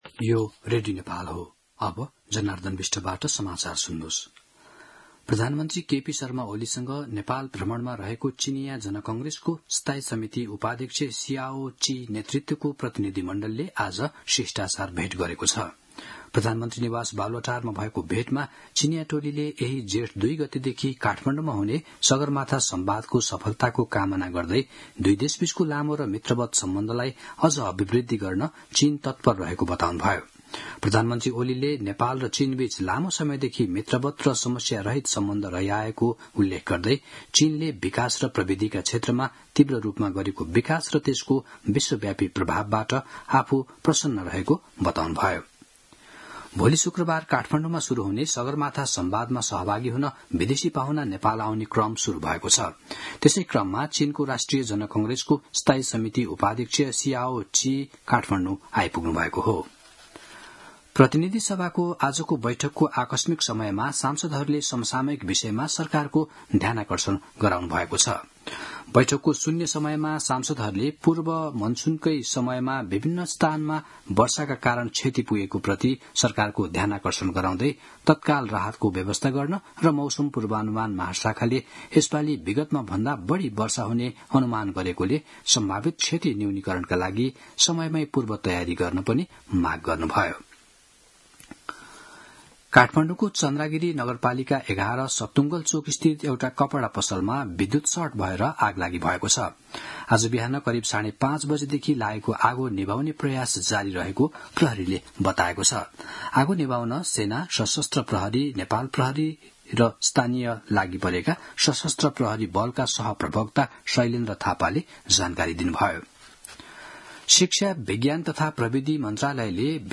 दिउँसो १ बजेको नेपाली समाचार : १ जेठ , २०८२